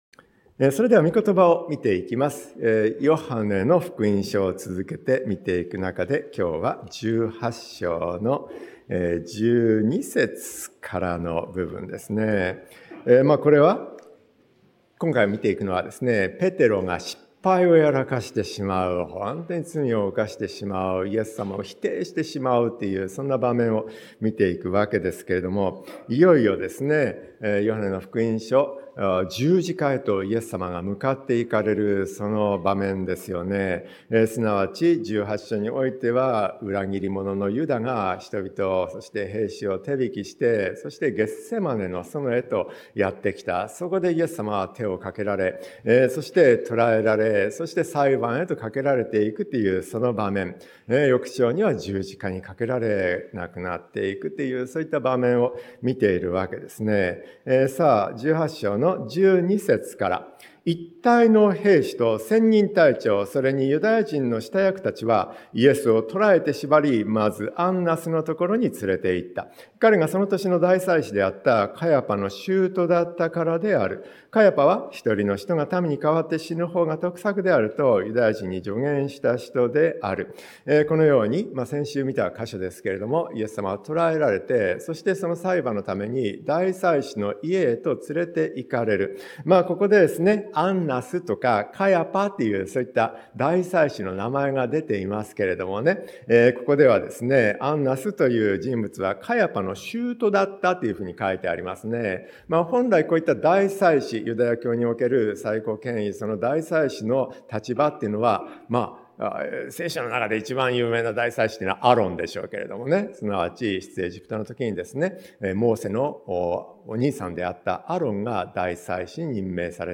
ペテロの失敗 説教者